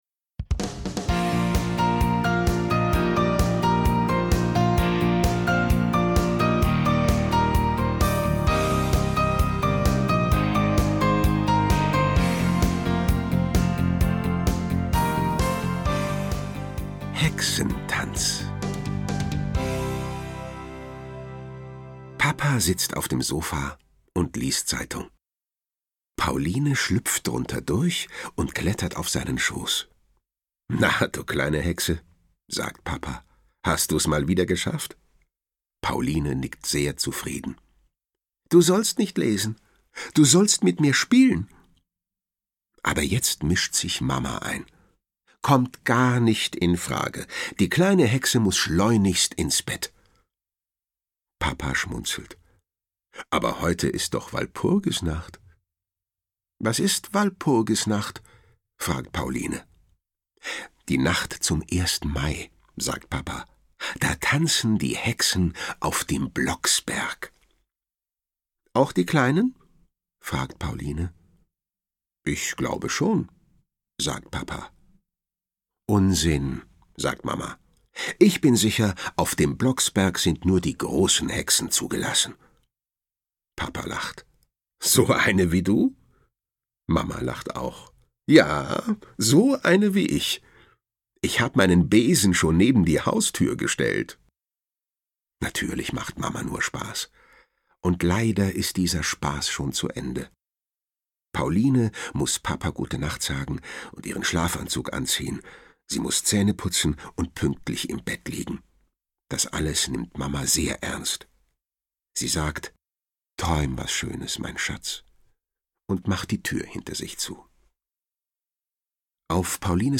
Erzählungen rund ums Jahr